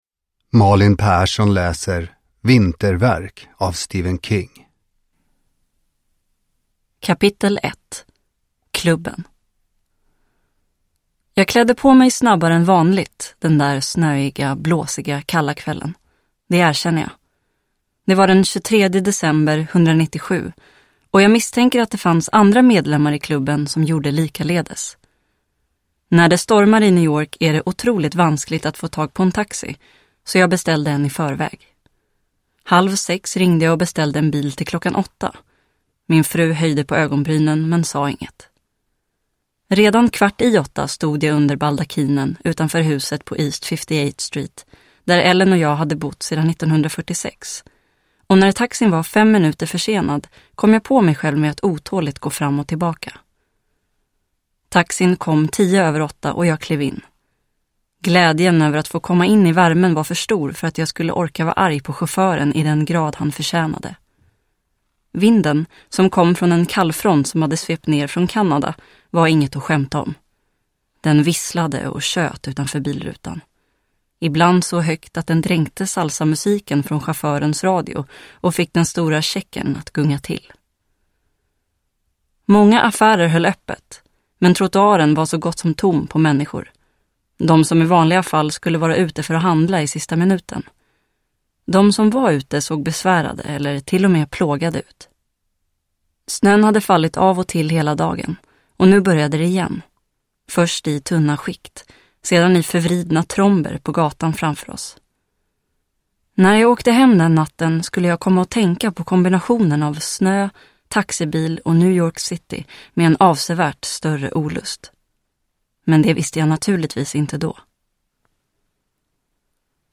Vinterverk : en av berättelserna ur novellsamlingen "Årstider" – Ljudbok